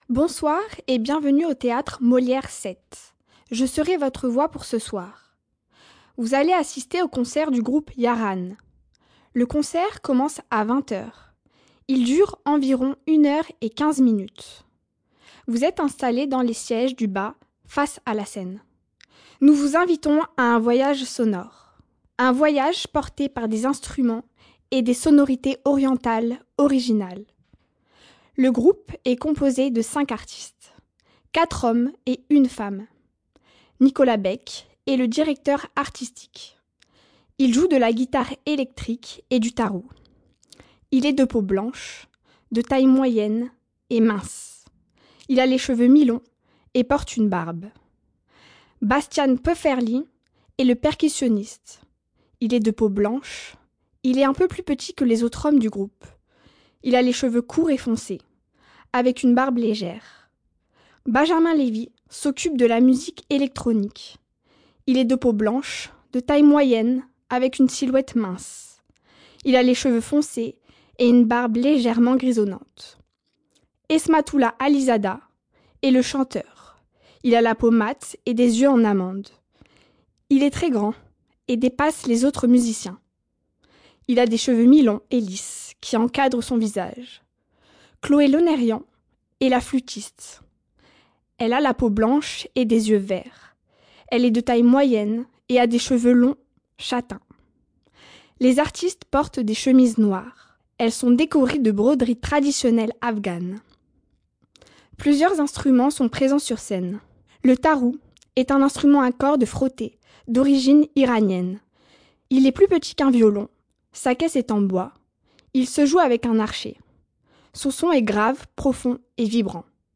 Feuille de salle auditive